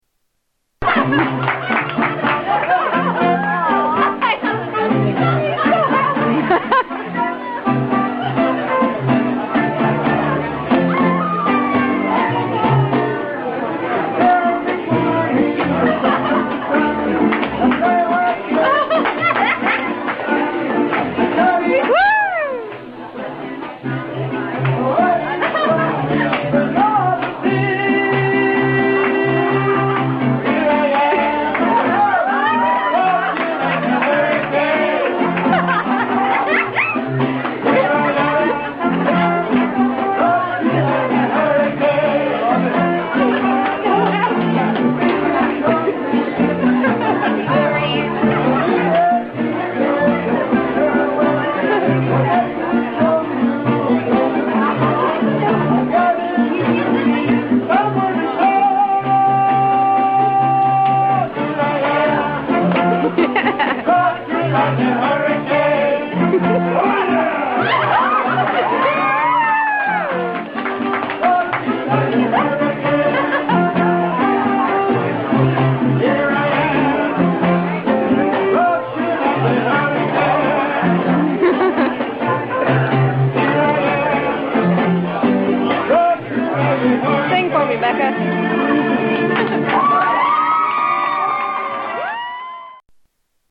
Mariachi Band